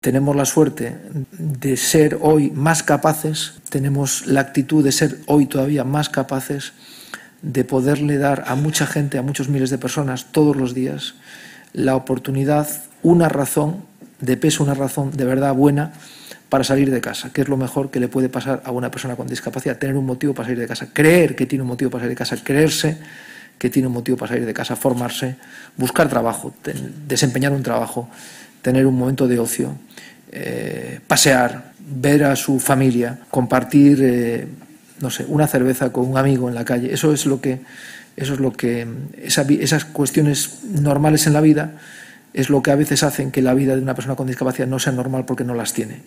La sede madrileña de Fundación ONCE reunió a estas 25 empresas y entidades en el  acto ‘Lazos de futuro’, celebrado el 18 de noviembre de manera presencial y telemática, con el objetivo de expresar públicamente el agradecimiento de las fundaciones del Grupo Social ONCE a los particulares, empresas e instituciones que colaboran con ellas a través de programas, proyectos e iniciativas que ayudan a avanzar en la igualdad de los derechos de las personas con discapacidad y sus familias, y por su compromiso con la mejora de la calidad de vida de las personas con discapacidad.